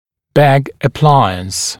[beg ə’plaɪəns][бэг э’плайэнс]аппарат Бегга